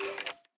minecraft / sounds / mob / skeleton / say3.ogg